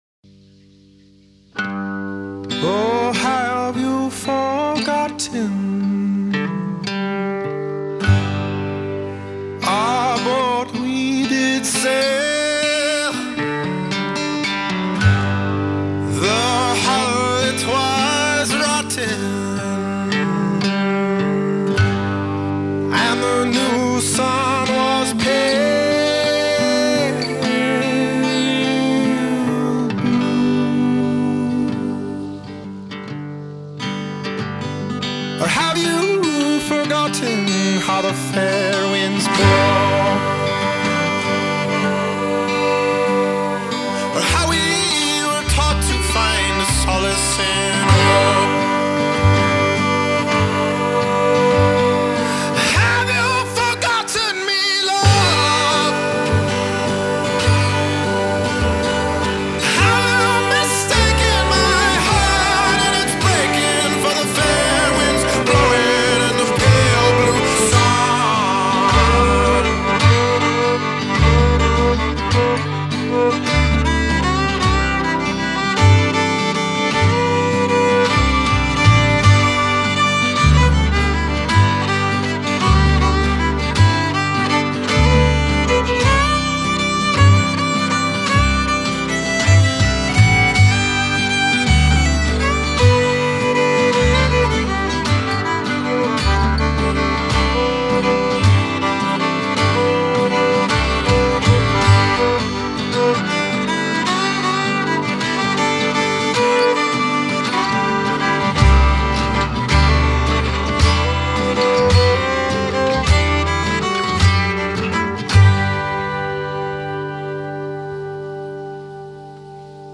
Guitar/Vox
Fiddle
Upright Bass